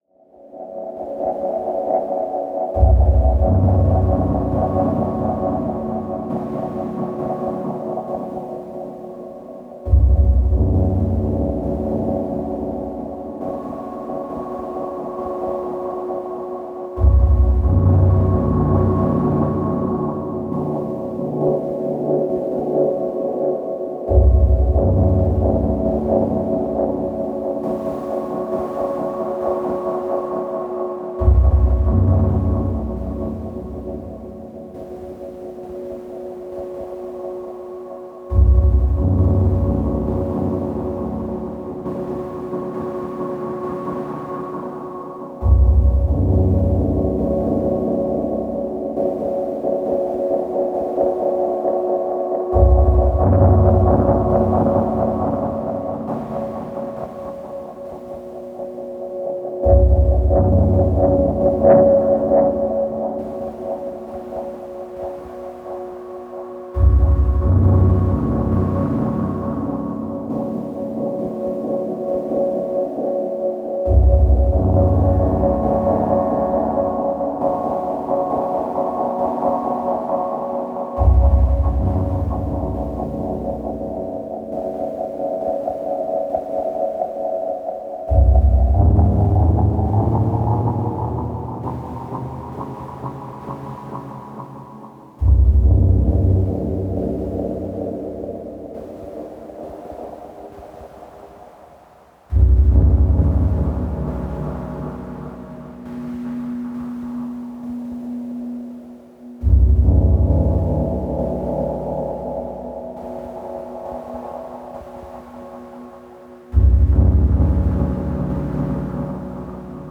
experimented with using the ms-20 as kind of a filter box/effects unit, putting it in the feedback path of a tape delay. i can then add some unpredictable movement to the delay by sequencing the vca/filters of the ms-20!